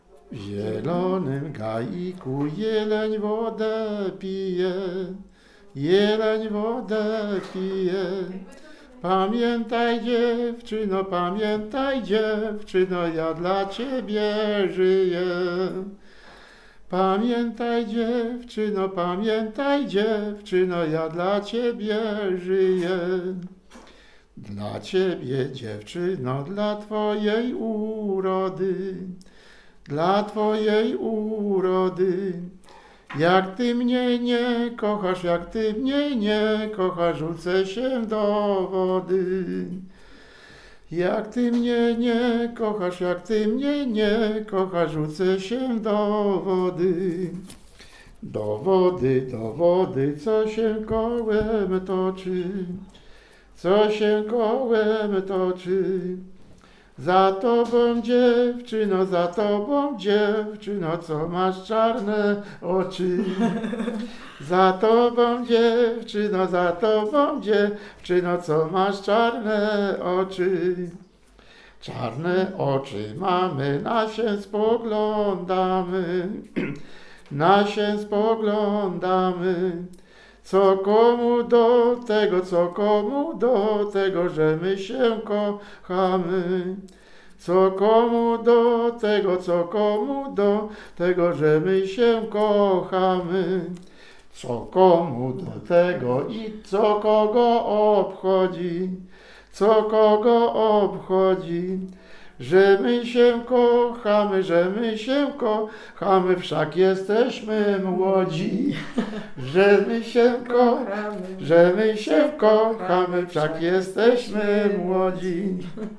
Utwór zarejestrowano w ramach nagrywania płyty "Od wschodu słońca...